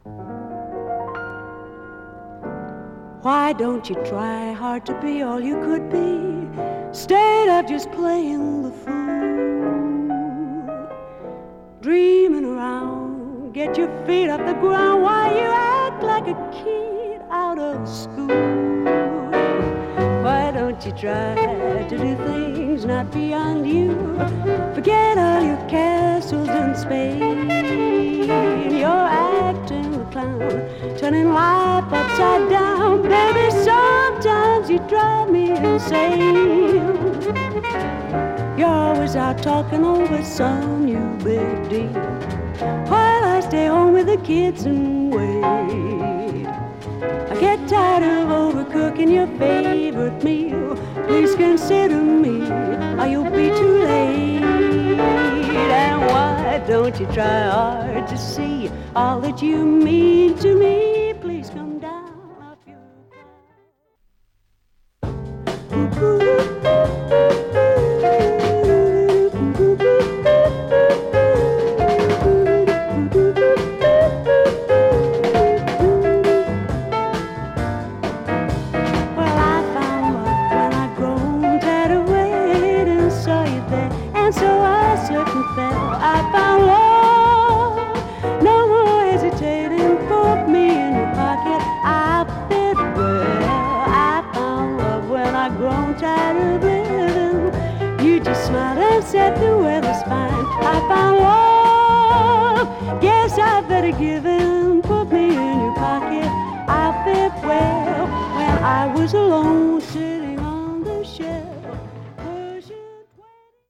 正統なジャズ演奏の上をボーカルを中心としたアルバム。
甘美なムーディー歌モノ
軽快なスイング
フルートをフューチャーした洒脱なジャズボサ